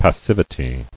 [pas·siv·i·ty]